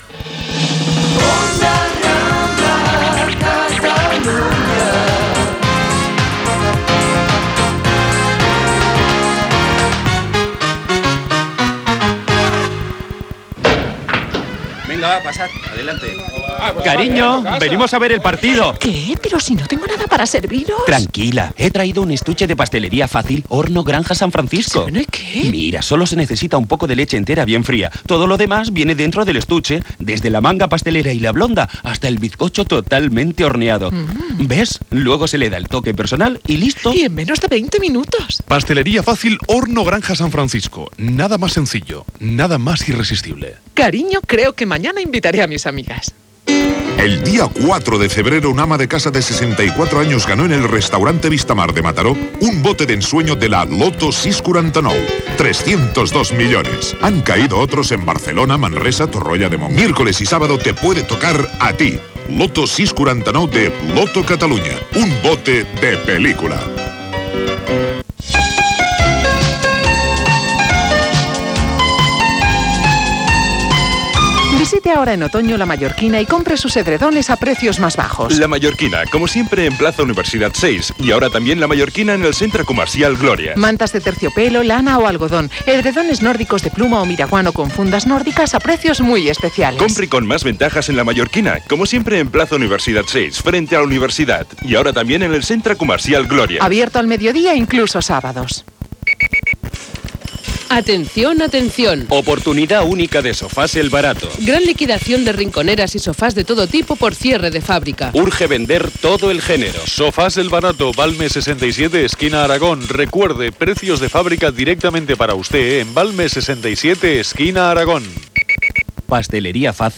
Indicatiu, publicitat, i inici del programa amb els Premis Ondas 1995.
Info-entreteniment
FM